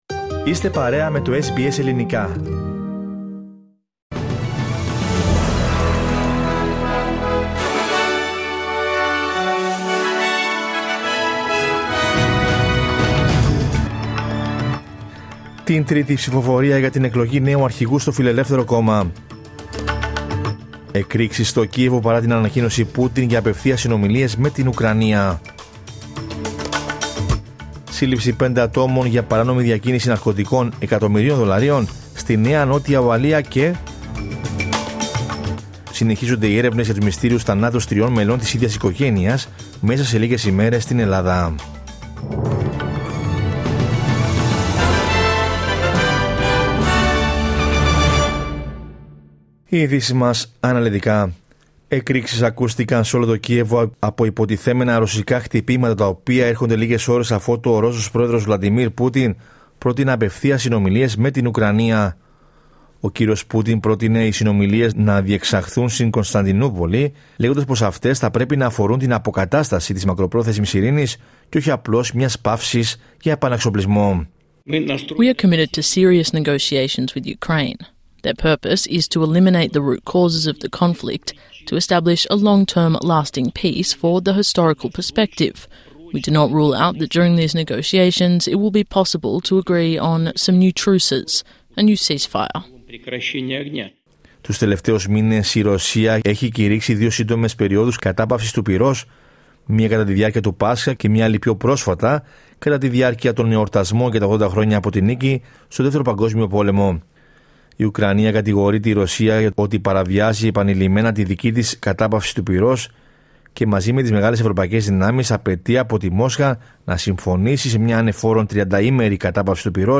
Δελτίο Ειδήσεων Κυριακή 11 Μαΐου 2025